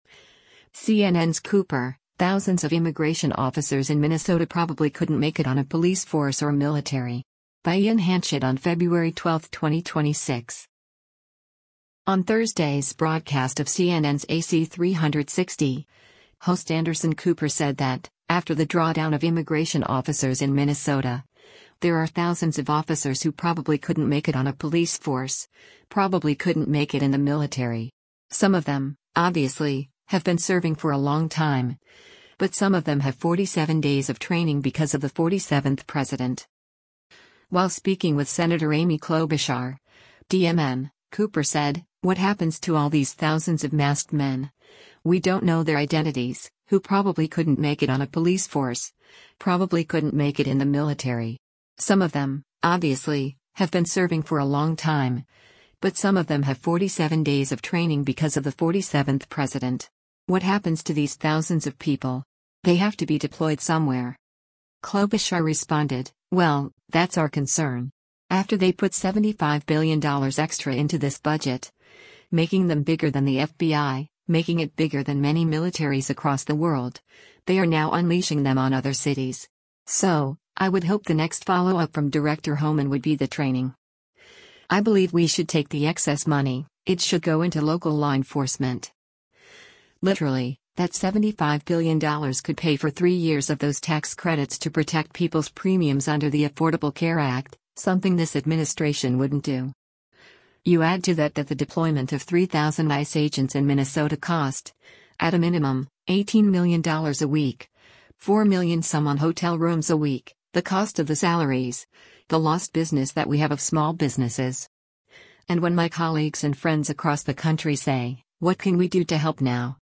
On Thursday’s broadcast of CNN’s “AC360,” host Anderson Cooper said that, after the drawdown of immigration officers in Minnesota, there are “thousands” of officers “who probably couldn’t make it on a police force, probably couldn’t make it in the military.
While speaking with Sen. Amy Klobuchar (D-MN), Cooper said, “What happens to all these thousands of masked men — we don’t know their identities — who probably couldn’t make it on a police force, probably couldn’t make it in the military.